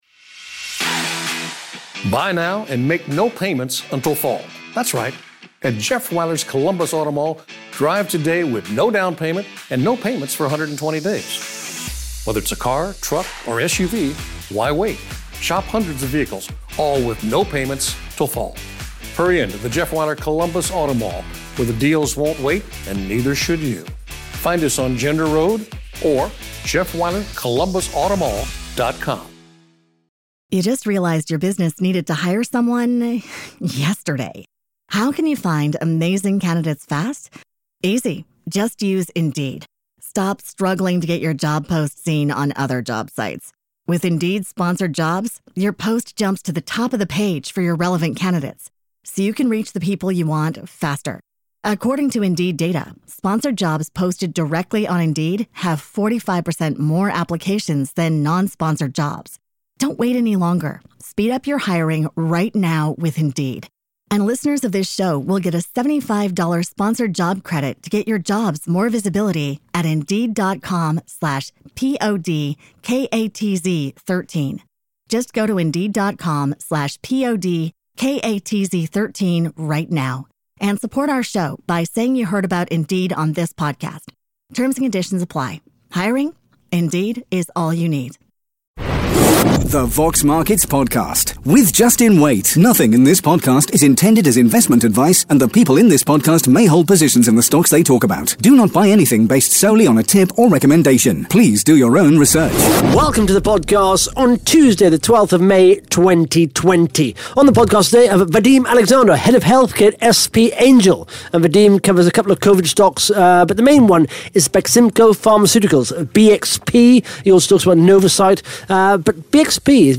(Interview starts at 15 minutes 14 seconds) Plus the Top 5 Most Followed Companies & the Top 5 Most Liked RNS’s on Vox Markets in the last 24 hours.